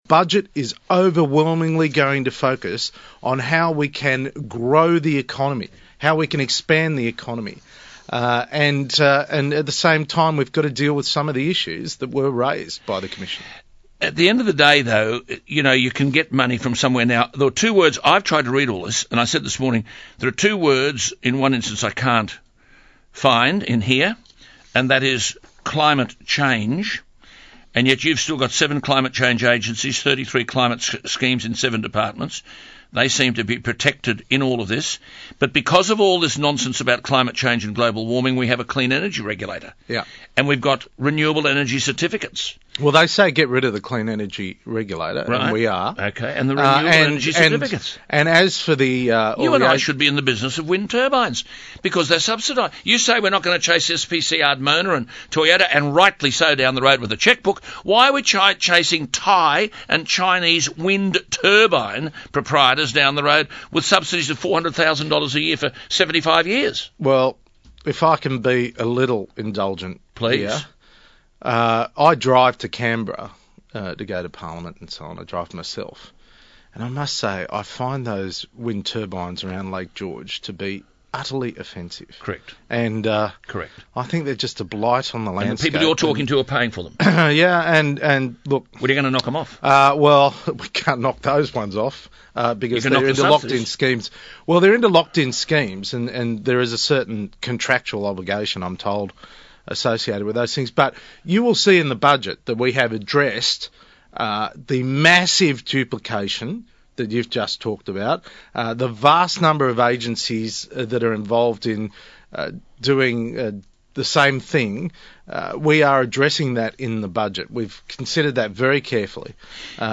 Last Friday, the Federal Treasurer, Joe Hockey (aka Shrek) met with Alan Jones on his Breakfast radio show on 2GB.